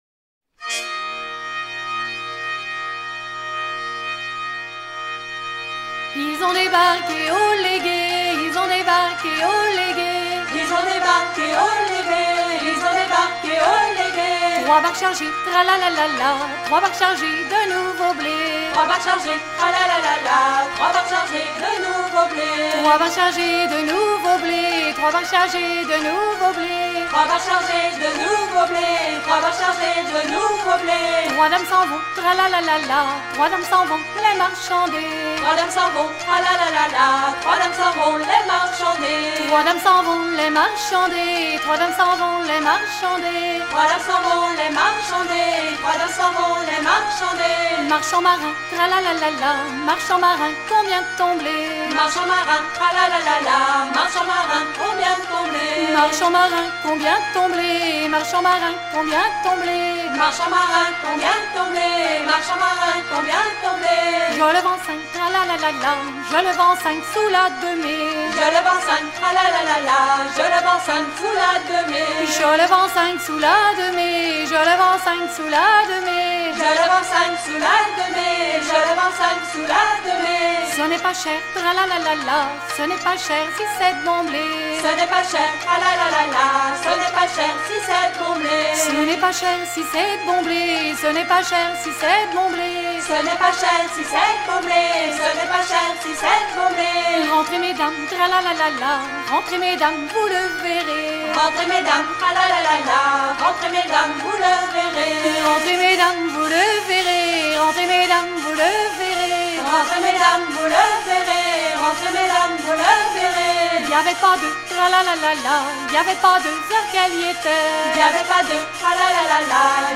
version collectée lors d'une veillée à Saint-Carreuc en 1970
danse : passepied